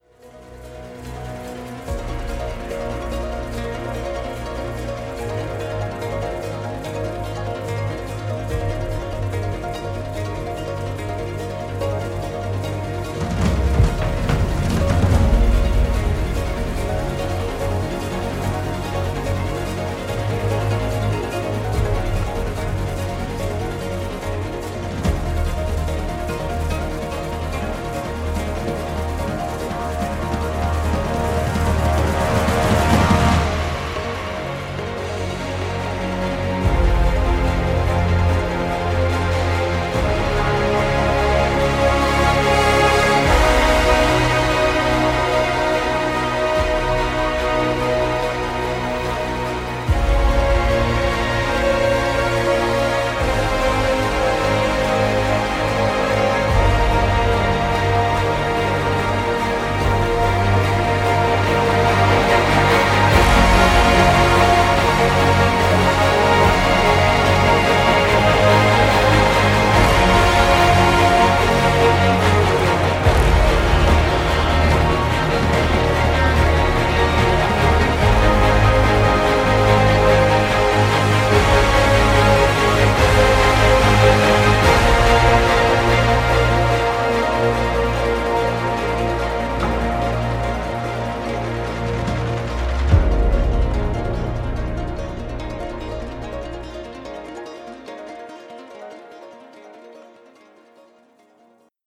为了获得我们一直在寻找的宽广的黄铜声音，我们将演奏者均匀地坐在一个巨大的音乐厅的舞台上。
撞击，打击垫，BRAAAM，合成贝司，有节奏的无人机等。
-在大型音乐厅录制。
-三个不同的部分：10个角，10个长号，3个Tubas / 3个Cimbassos（部落）。
-记录在舞台中央，宽阔的座位上。
-三个麦克风位置：关闭，房间，混合。